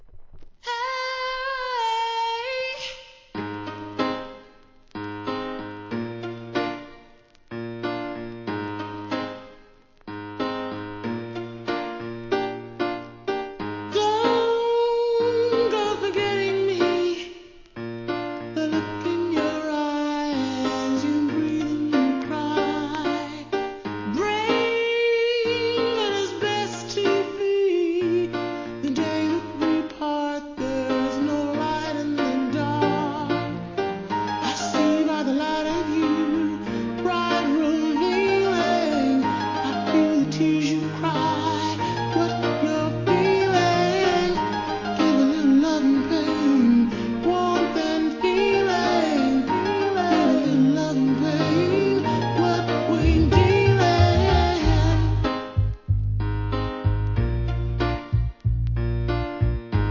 HIP HOP/R&B
ファルセットが美しいシンガーのアコースティックなメローチュ〜ン！